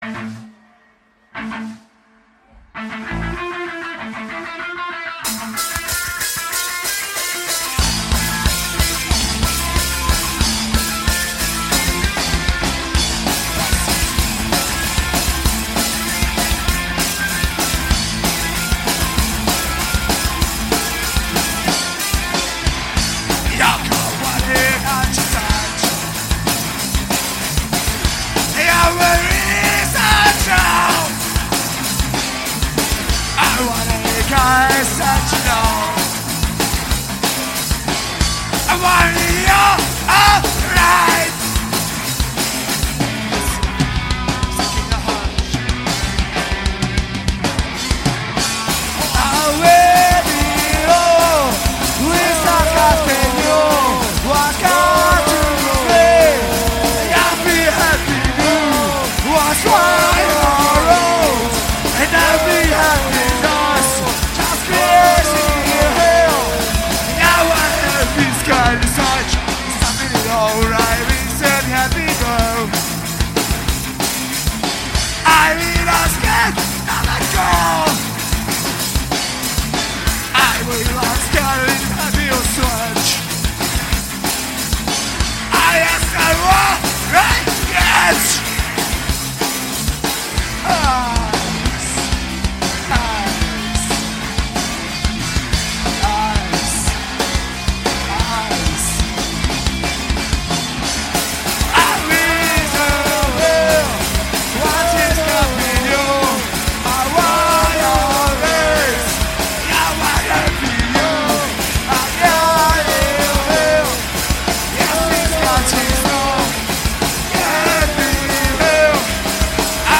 Žánr: Metal/HC
live vystoupení